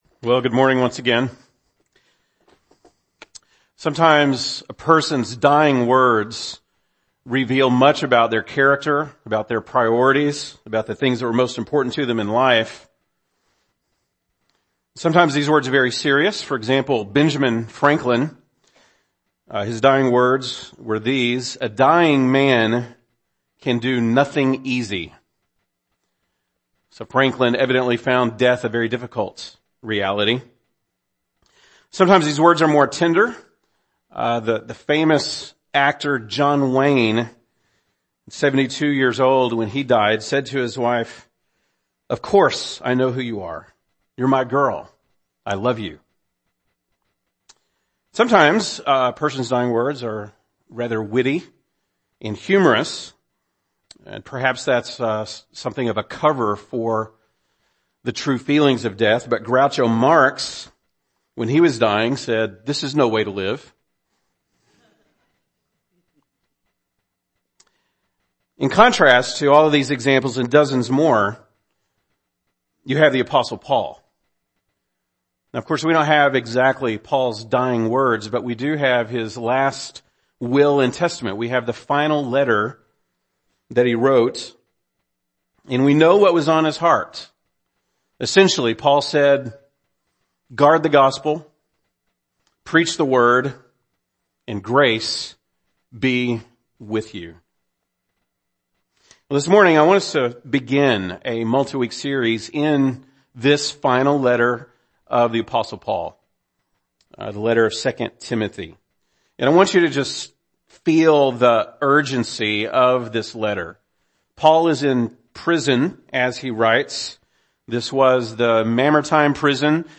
April 30, 2017 (Sunday Morning)